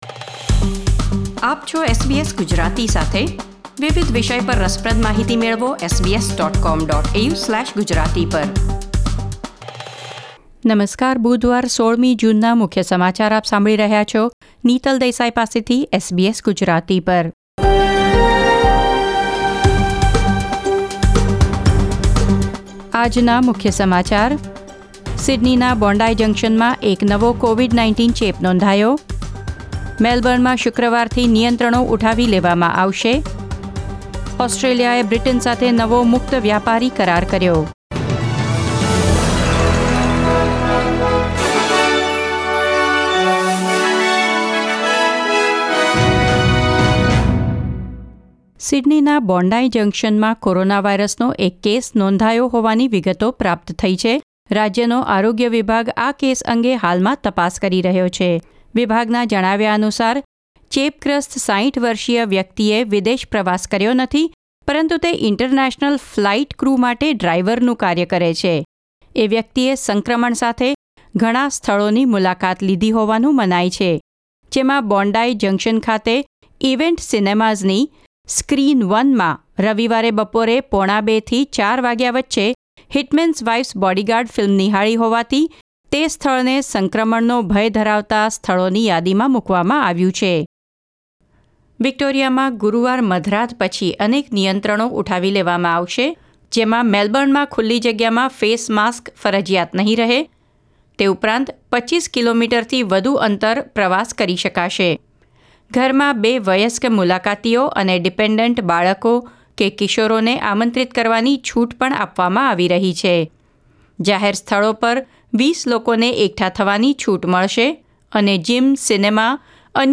SBS Gujarati News Bulletin 16 June 2021